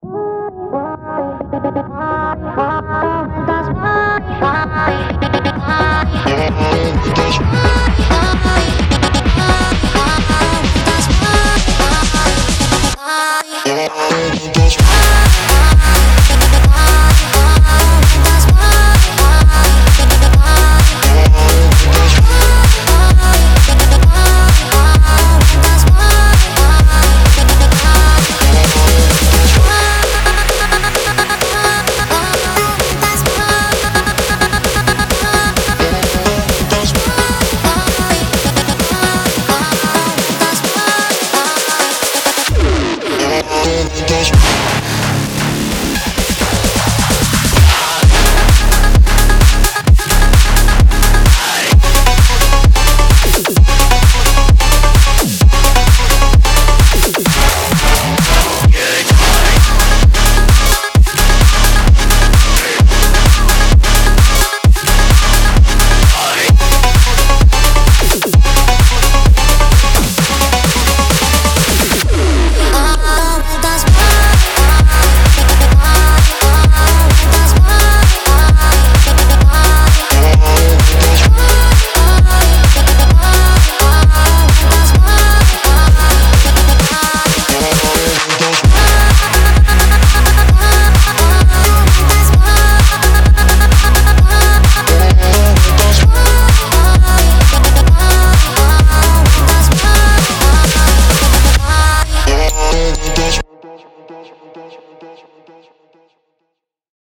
BPM130-130
Audio QualityPerfect (High Quality)
EDM Phonk song for StepMania, ITGmania, Project Outfox
Full Length Song (not arcade length cut)